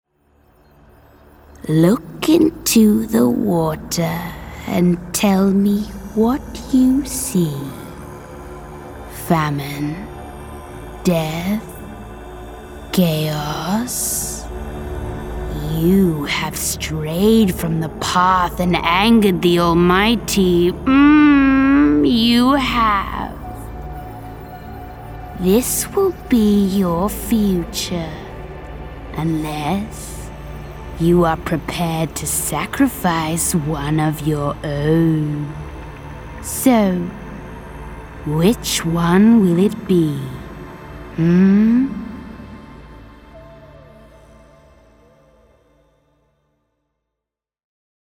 Female
English (Australian)
Character / Cartoon
Gaming Example